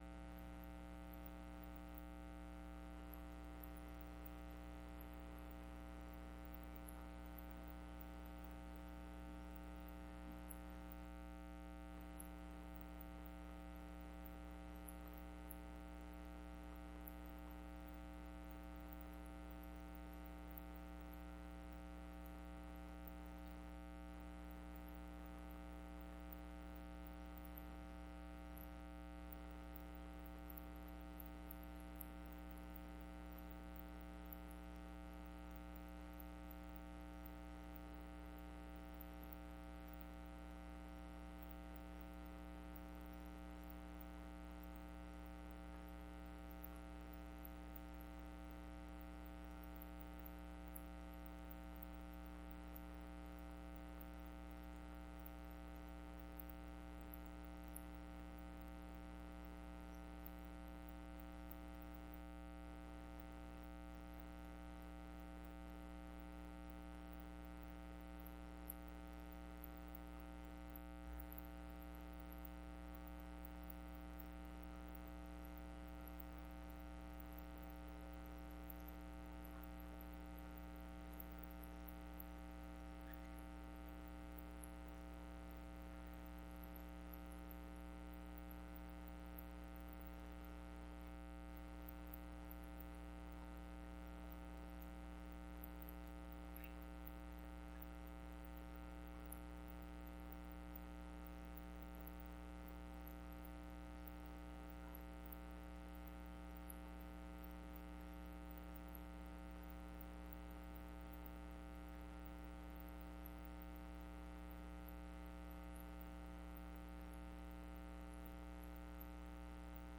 Sermon-9-1-19.mp3